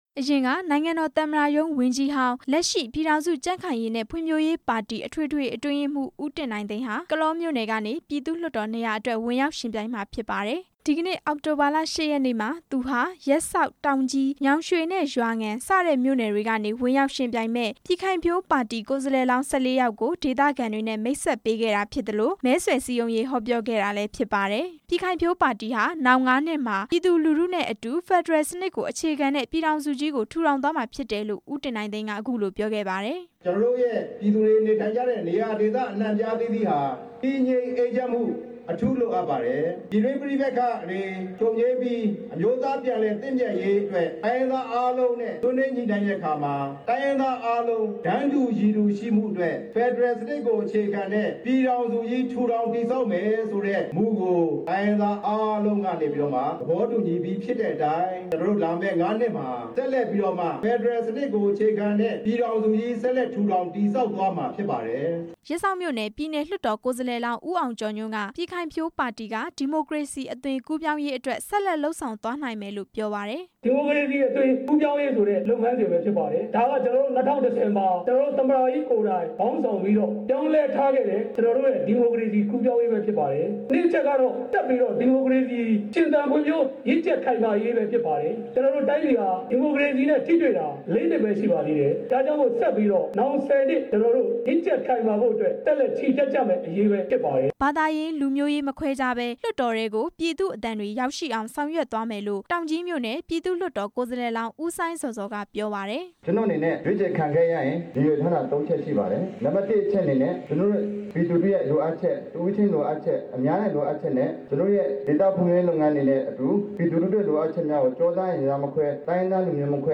ပြည်ထောင်စုကြံ့ခိုင်ရေးနဲ့ ဖံ့ွဖြိုးရေးပါတီ အထွေထွေအတွင်းရေးမှူး ဦးတင်နိုင်သိန်းနဲ့ လွှတ်တော်ကိုယ်စားလှယ်လောင်း ၁၄ ယောက်ဟာ ရှမ်းပြည်နယ် တောင်ကြီးမြို့က အဝေရာမီးပုံးပျံကွင်းမှာ ဒီနေ့ မဲဆွယ်စည်းရုံး ဟောပြောကြပါတယ်။